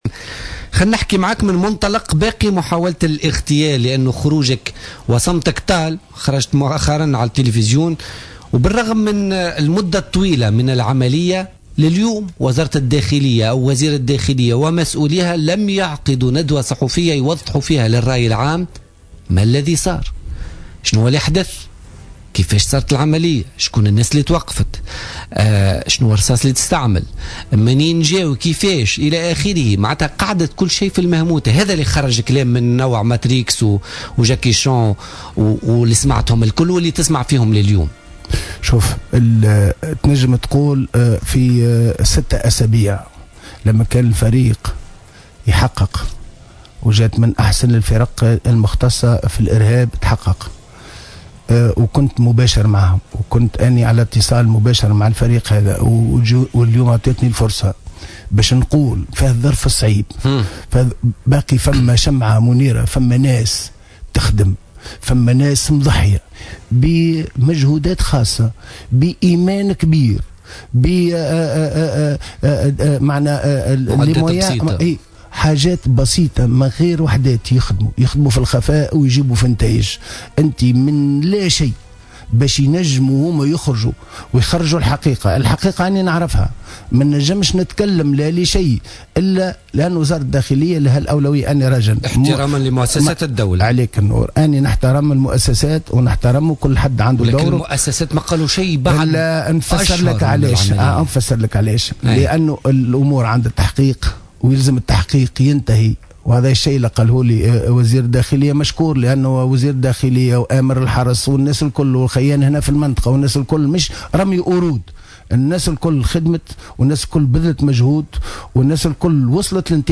قال النائب والقيادي في نداء تونس رضا شرف الدين في تصريح للجوهرة أف أم في برنامج بوليتكا لليوم الخميس 26 نوفمبر 2015 إنه يعلم كافة التفاصيل عن عملية محاولة اغتياله وعن الجهات التي خططت لها مضيفا أن وزارة الداخلية ستكشف عنها في الوقت المناسب.